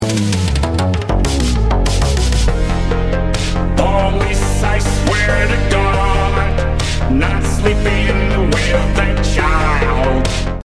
squidward-police-i-swear-to-god-made-with-Voicemod-technology.mp3